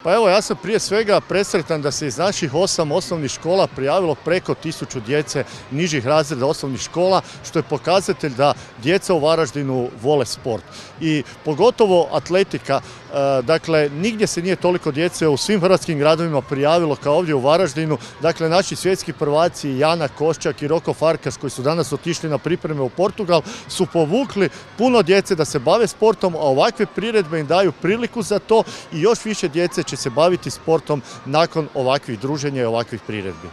Gradonačelnik Varaždina Neven Bosilj: